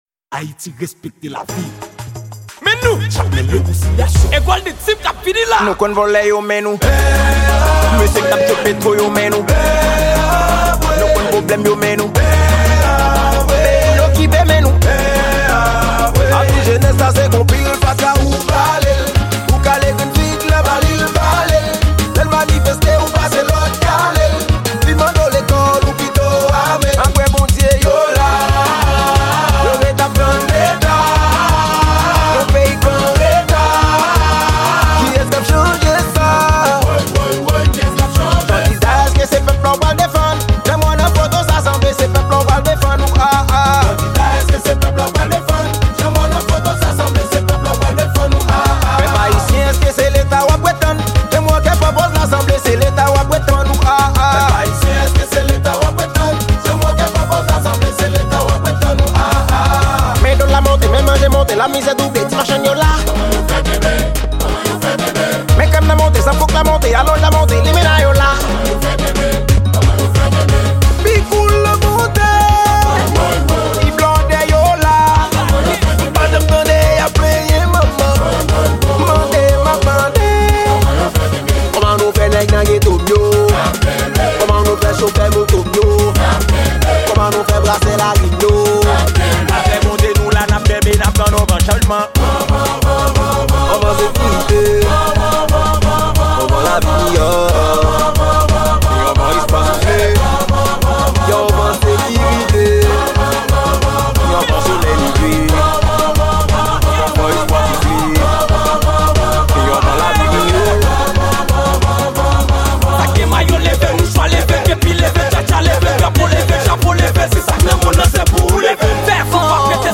Genre: kanaval.